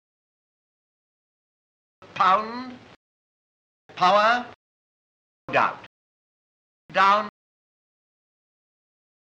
All the recordings have been subjected to lossy MP3 compression at some time during their lives.
Listen to the [aɔ]-like MOUTH by Stanley Baldwin (Figure 2):